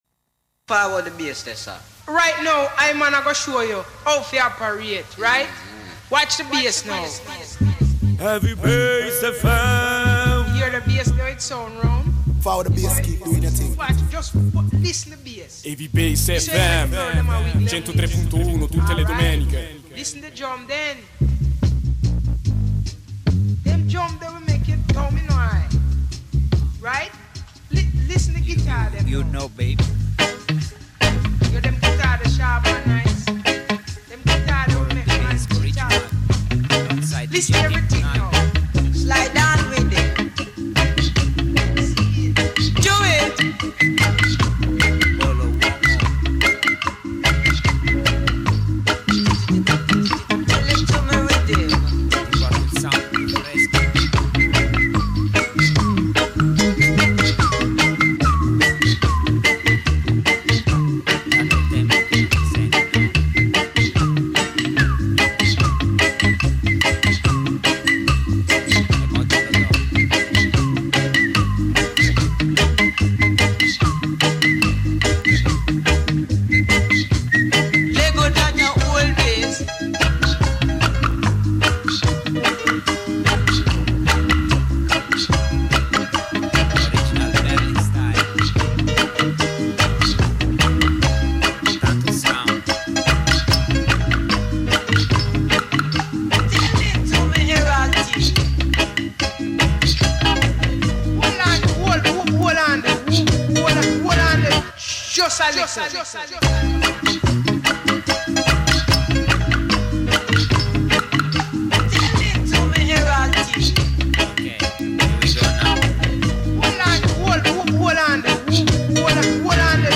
heavy bassline grooves. Berlin/Bolo style.